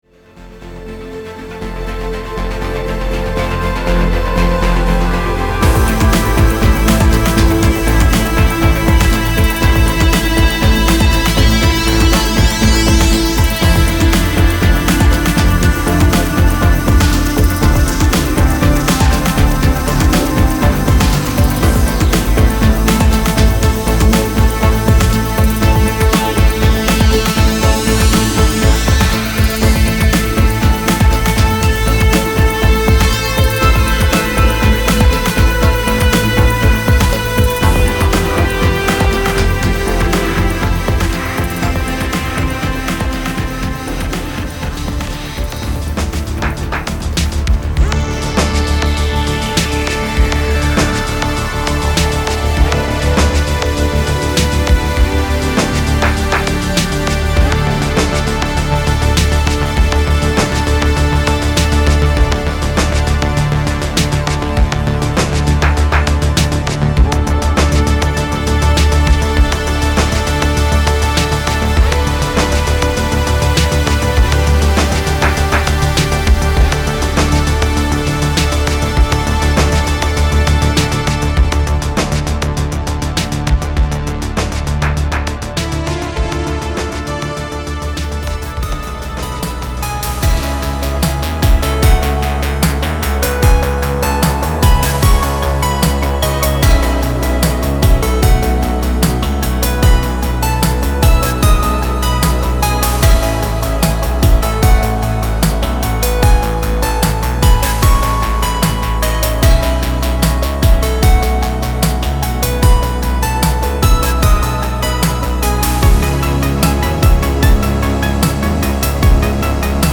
rhythmic, melodic and sophisticated electronic albums
with Focusrite preamps and A/D converters